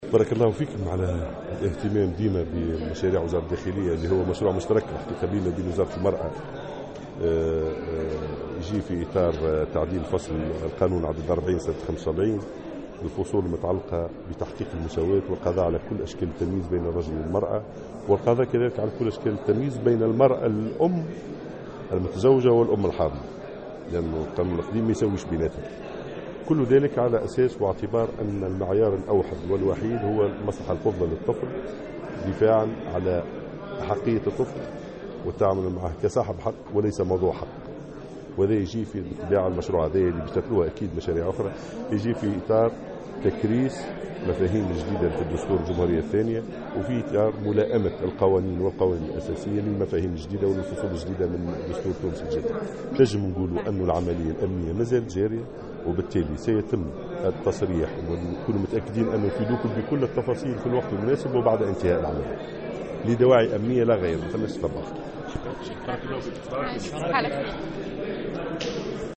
تحدث وزير الداخلية، ناجم الغرسلي في تصريح لمراسلنا اليوم الثلاثاء، عن العملية التي تنفذها الوحدات الامنية بسوسة حيث بين أنها مازالت مستمرة، متعهدا بالتصريح بنتائجها وتفاصيلها مباشرة بعد انتهائها، مشيرافي الآن ذاته إلى أن التحفظ عن ذكر أية تفاصيل الآن هو لدواعي أمنية.